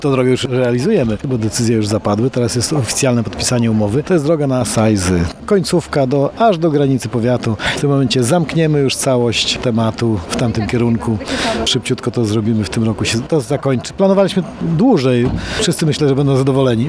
Cieszy się także Marek Chojnowski, starosta powiatu ełckiego. Wyjaśnia, że fundusze pozwolą szybciej ukończyć drogę do Sajz.